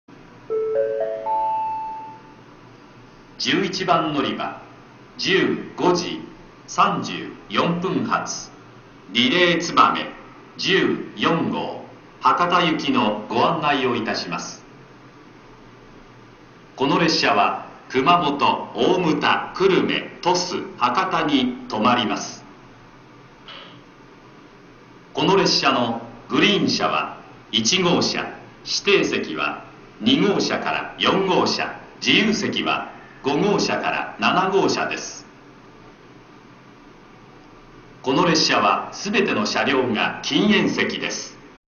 在来線の放送とは声・放送内容が大幅に異なっていますが、詳細であることには変わりありません。
ホームドア開閉時には、駅員が注意放送をする為、発車放送、到着案内放送では駅員放送が被ります。
案内放送（リレーつばめ14号　博多行き）